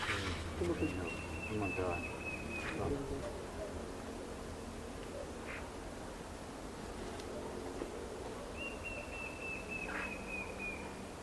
White-shouldered Fire-eye (Pyriglena leucoptera)
Sex: Male
Life Stage: Adult
Location or protected area: Parque Provincial Araucaria
Condition: Wild
Certainty: Observed, Recorded vocal